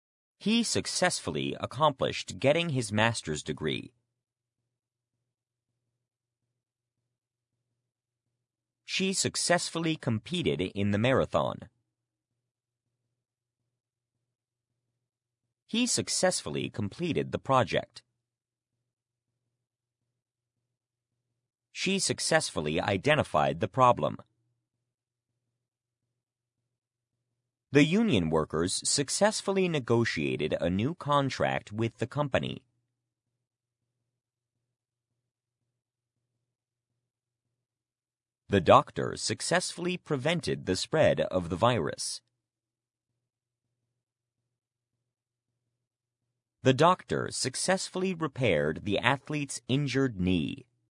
successfully-pause.mp3